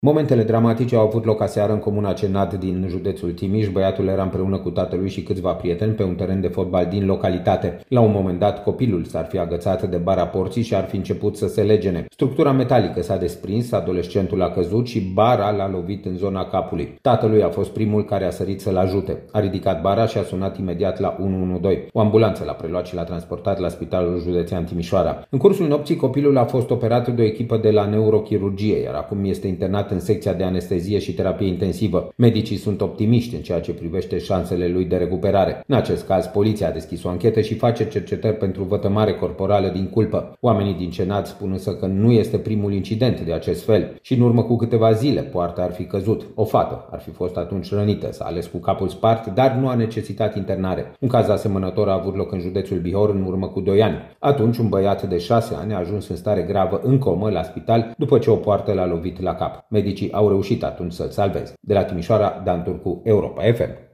15mar-12-CORESP-TM-poarta-cazuta-peste-copil.mp3